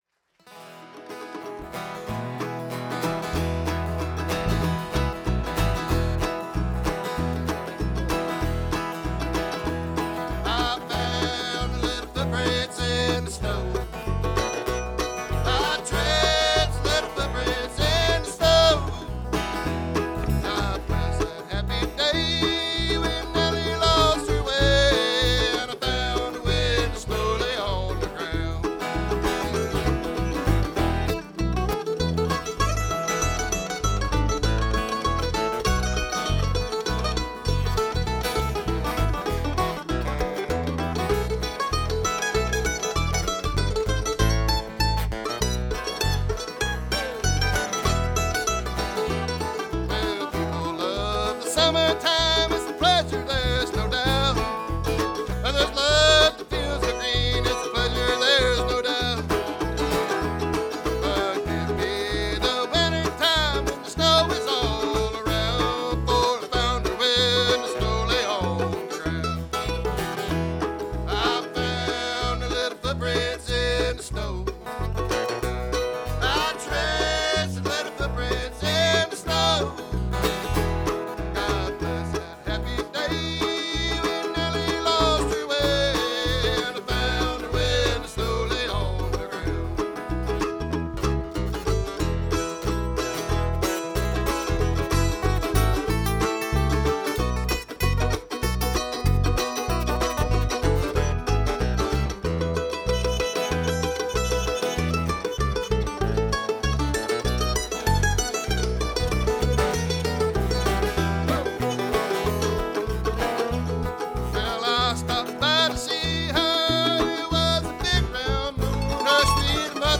Birdfest Music Festival Pinewood, South Carolina
guitar
mandolin
electronic bass